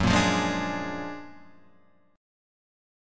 F#+M9 chord